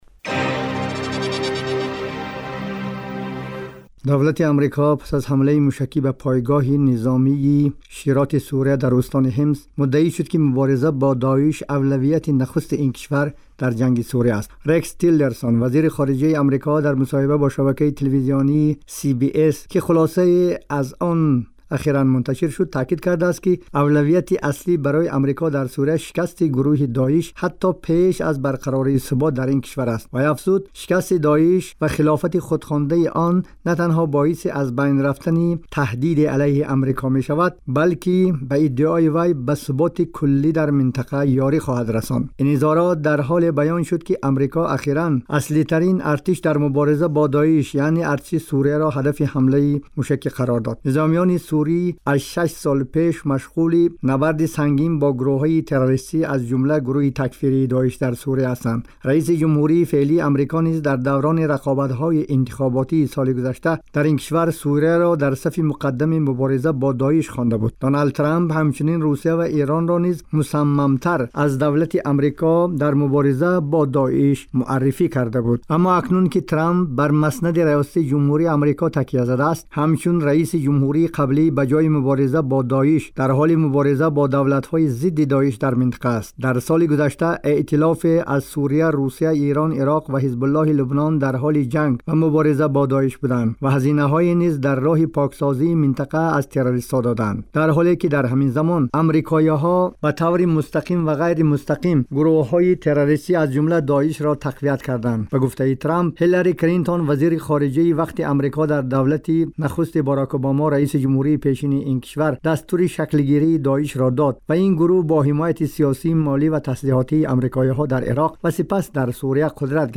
гузорише вижа